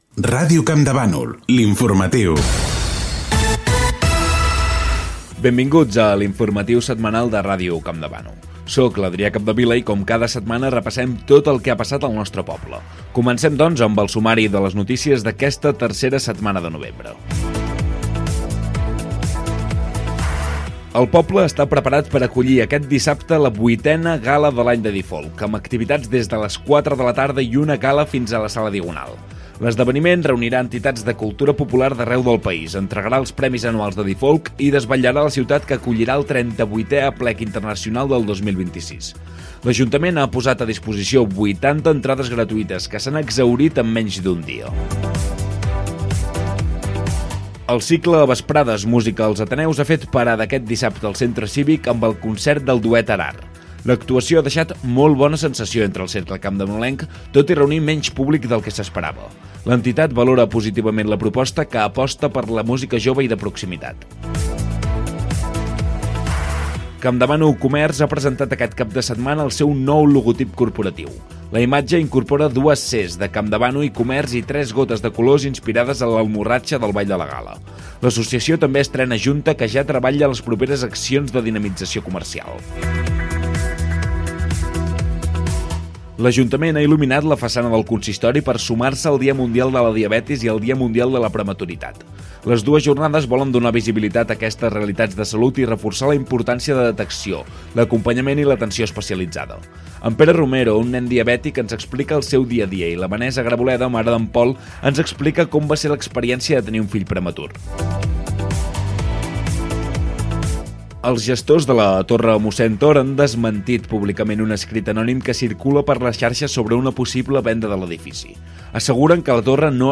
Careta, presentació, sumari informatiu
Informatiu